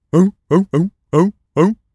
pgs/Assets/Audio/Animal_Impersonations/seal_walrus_bark_02.wav at master
seal_walrus_bark_02.wav